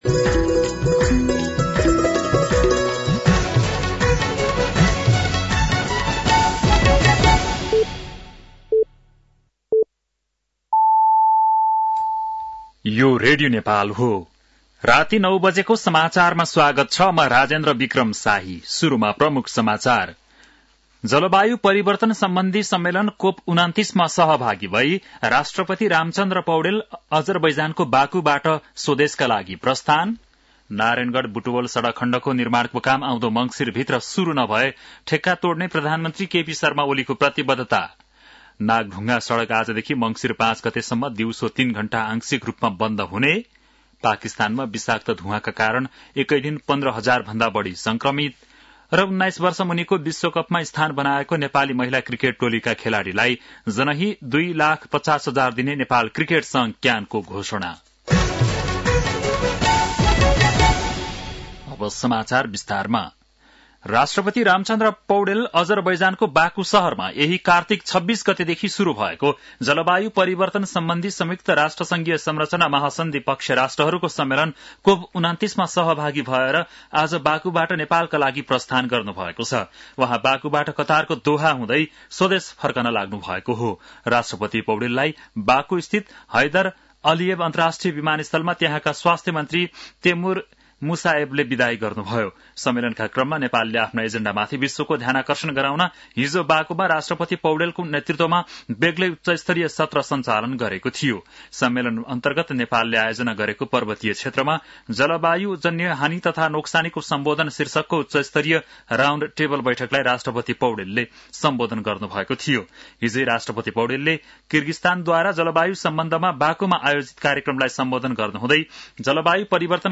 बेलुकी ९ बजेको नेपाली समाचार : ३० कार्तिक , २०८१
9-PM-Nepali-NEWS-7-29.mp3